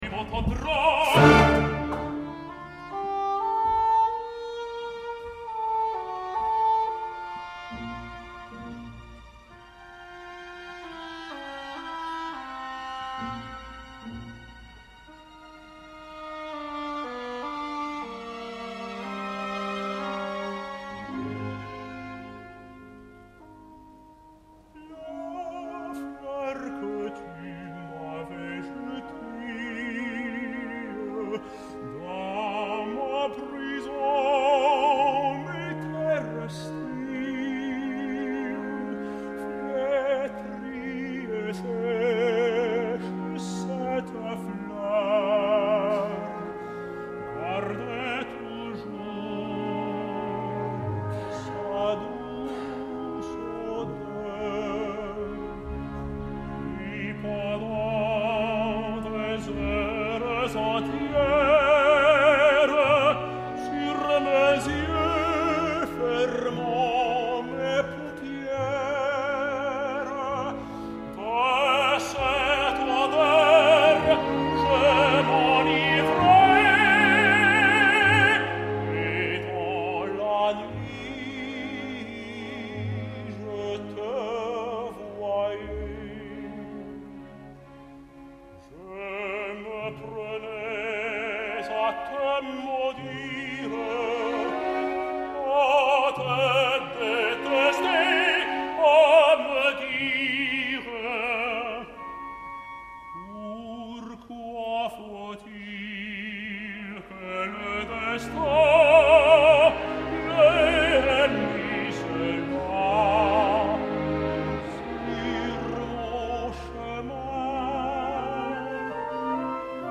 La veu no és gaire maca, no brilla, és una veu mate amb unes certes nassalitats, però el registre és generós i no mostra preocupants dificultats en la zona aguda. Mostra varietat i expressiva i apiana l’agut de l’ària de la flor, tal i com està escrit, però que posa malalt a molta gent.
Salle Favart, l’Opéra Comique, Paris, 30 de juny de 2009